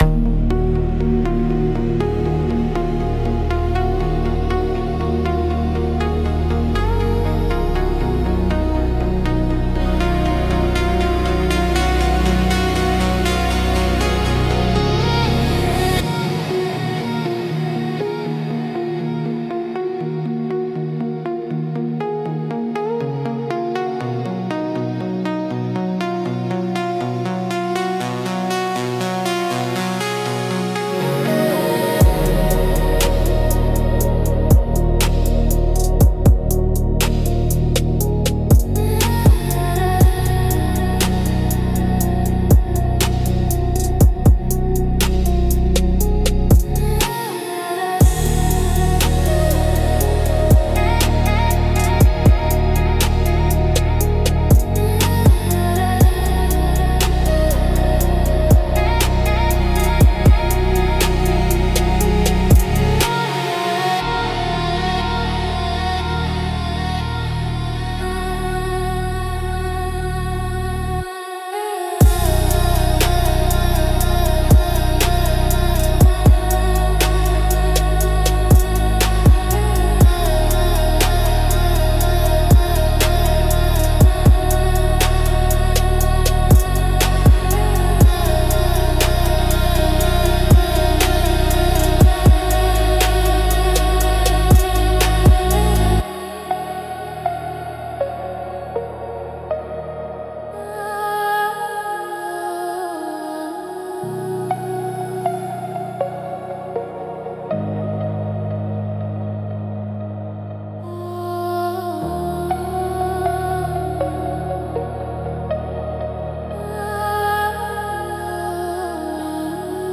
Instrumental - Fading Echo - 3.32